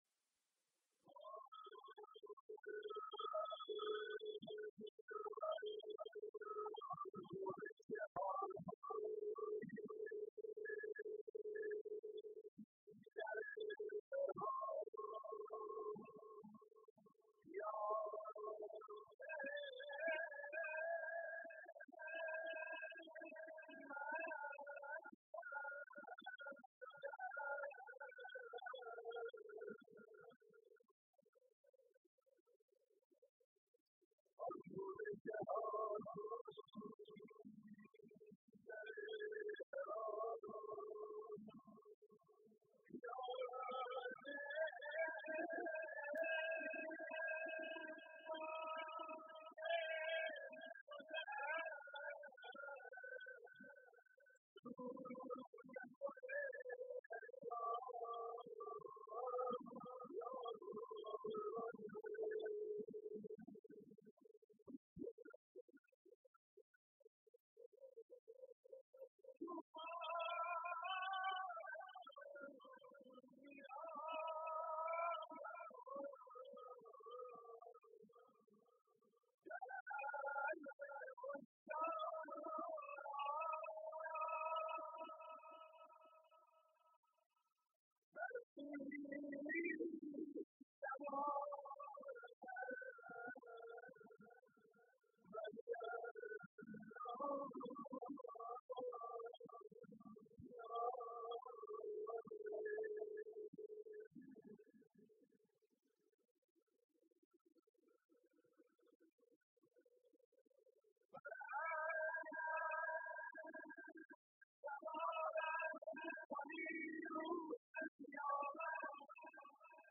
روضه
مناسبت : اربعین حسینی
مداح : محمود کریمی قالب : روضه